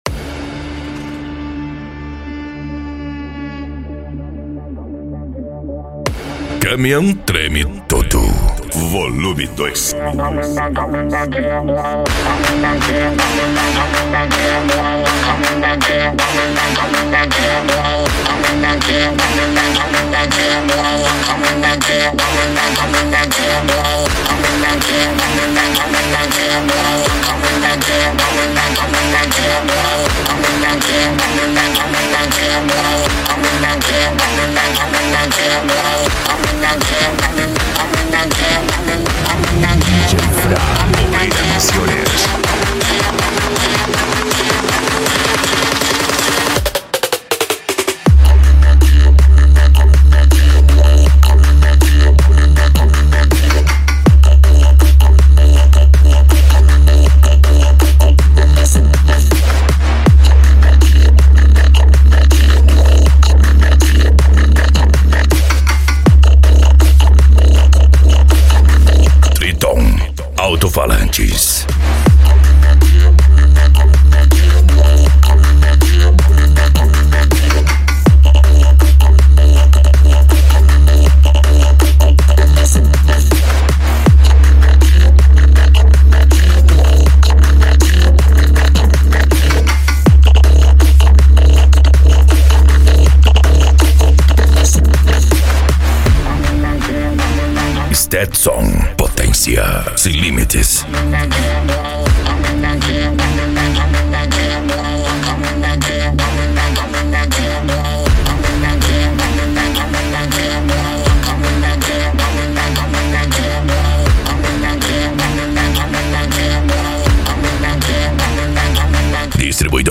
Modao
PANCADÃO
Remix